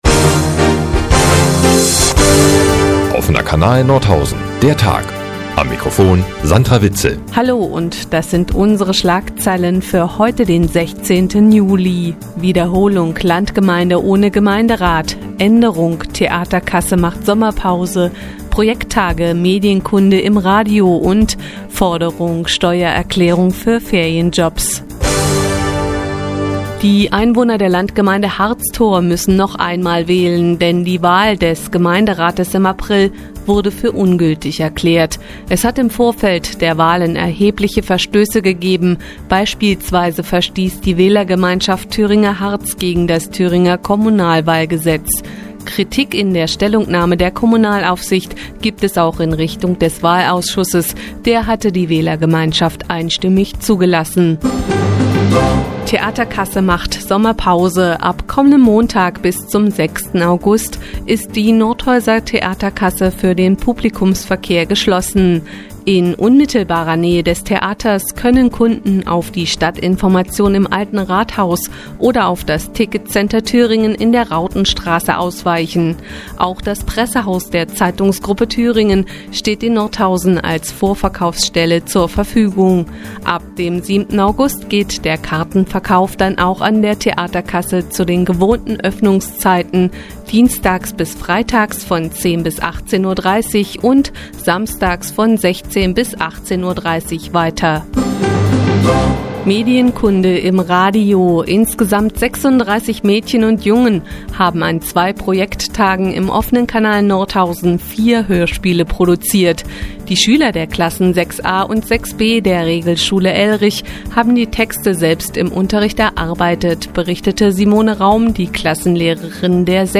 16.07.2012, 15:29 Uhr : Seit Jahren kooperieren die nnz und der Offene Kanal Nordhausen. Die tägliche Nachrichtensendung des OKN ist jetzt hier zu hören.